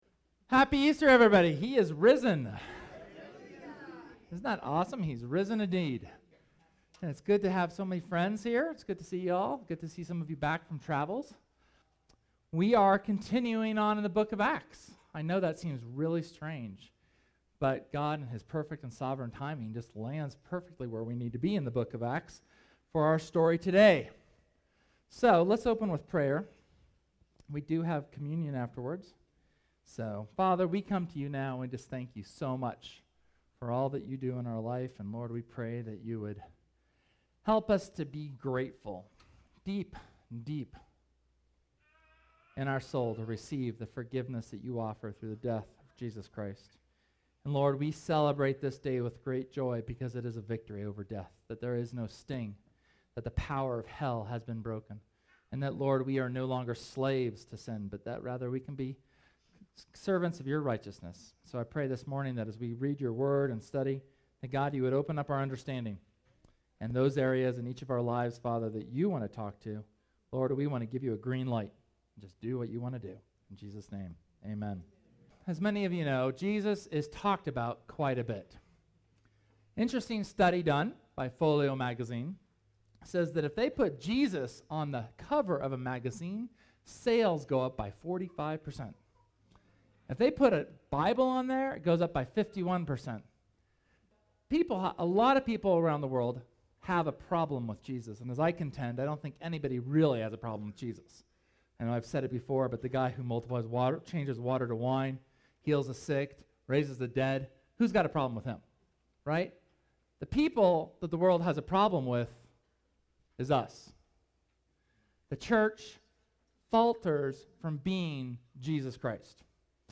SERMON: Go & Tell
Easter 2013 sermon on the resurrection and ascension of Christ as well as the selection of Matthias as an apostle and its correlation to the command to us to be Christ’s witnesses.